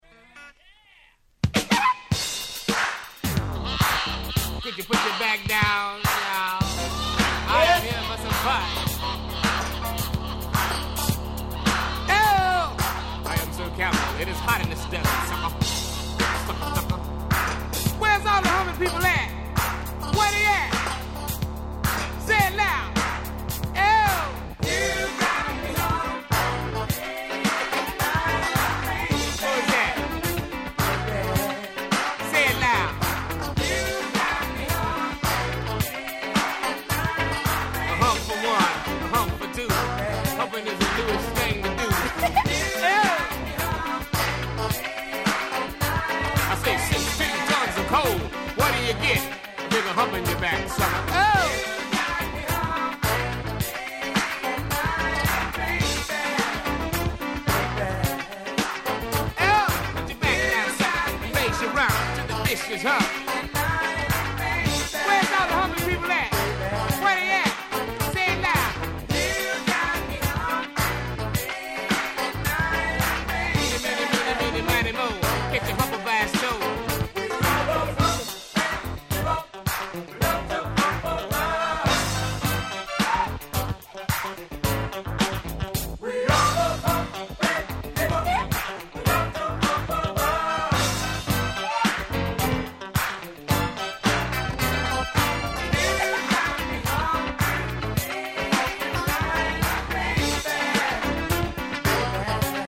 80' Nice Funk/Soul !!